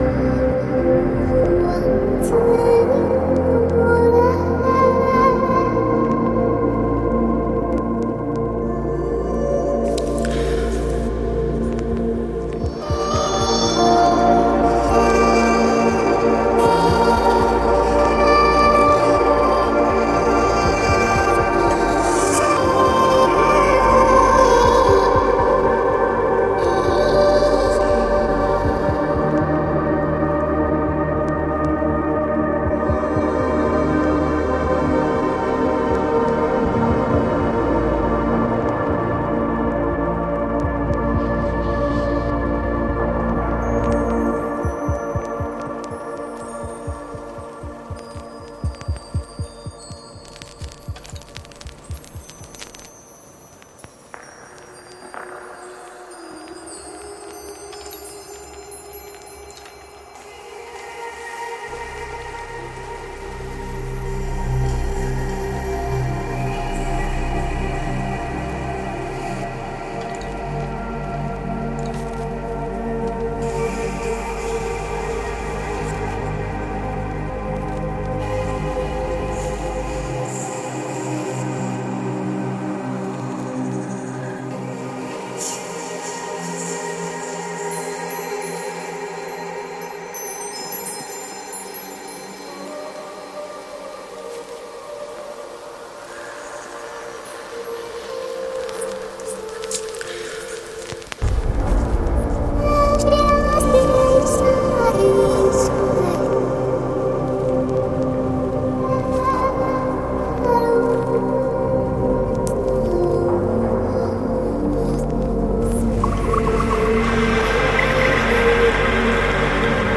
Ambient Electronica 12 Inch Ep